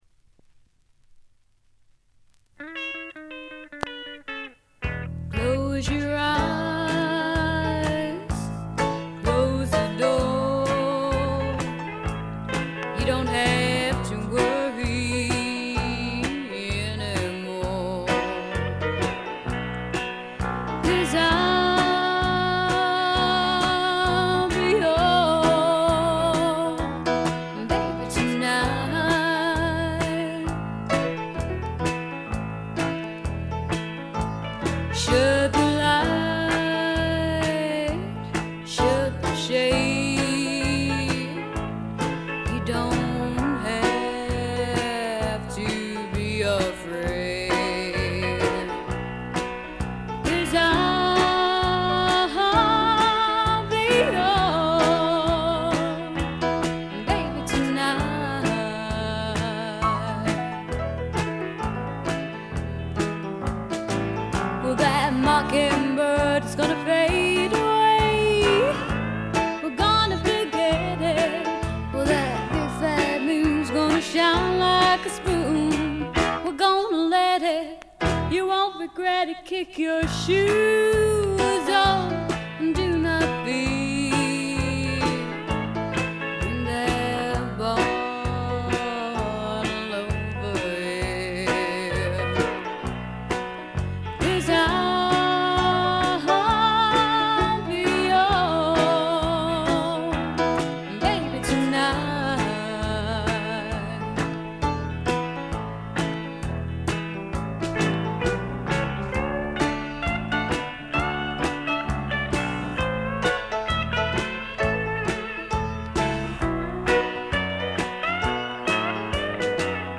イングランドの小さなレーベルに残された女性シンガーの好盤です。
試聴曲は現品からの取り込み音源です。